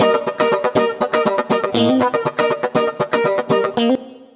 120FUNKY12.wav